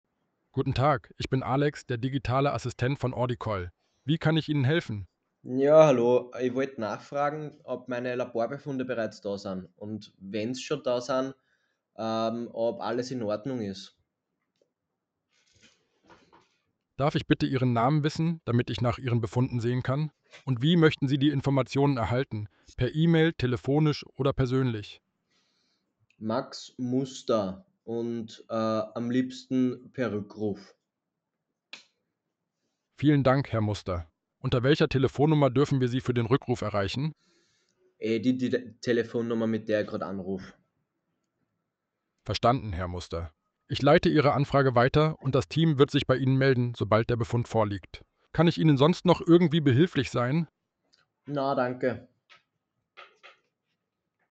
Die Hörprobe zeigt, wie Ordicall beim Entgegennehmen von Patientenanrufen unterstützt.